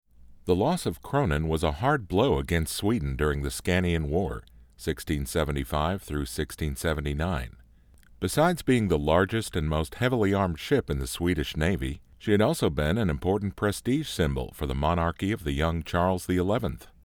A textured male baritone voice to make your script credible.
Sprechprobe: Industrie (Muttersprache):
A textured male baritone voice. Authoritative, warm, friendly, weary...whatever you need.